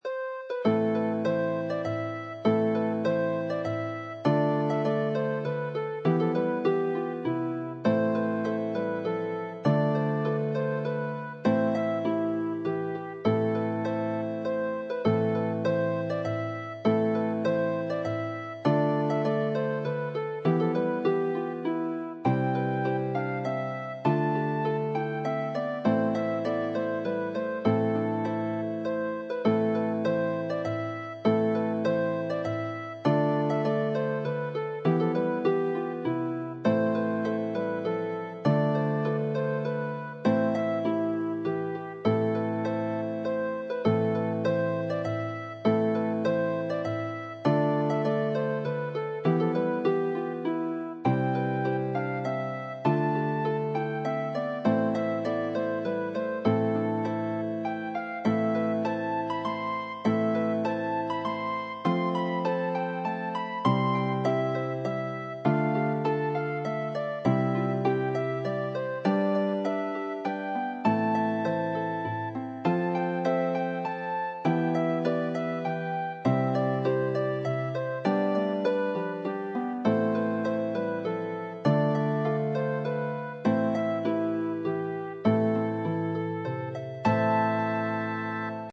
2-5 or more lever or pedal harps ~ $12.00
midi generated sample(harps 1-4)